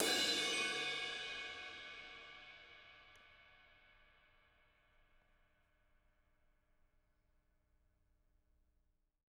R_B Crash B 02 - Room.wav